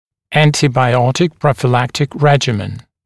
[ˌæntɪbaɪ’ɔtɪk ˌprɔfɪ’læktɪk ‘reʤɪmən][ˌэнтибай’отик ˌпрофи’лэктик ‘рэджимэн]режим профилактического приема антибиотиков